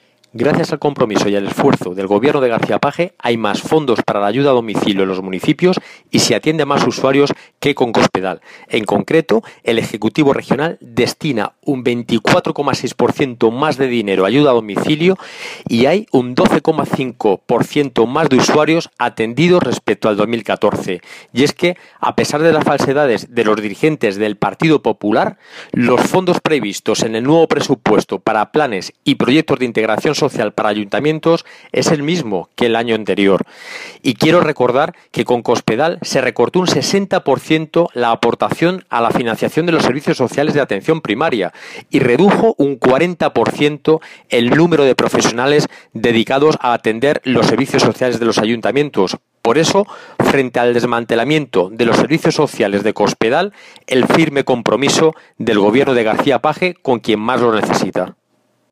Toledo, 22 de julio de 2017.- El diputado del Grupo socialista, José Luis Escudero, ha indicado que hoy hay más fondos para la ayuda a domicilio que se presta en los municipios de menor población de las zonas del Programa Regional de Acción Social (PRAS) y son más los usuarios que se benefician de este servicio que los que lo hacían con el anterior ejecutivo.
Cortes de audio de la rueda de prensa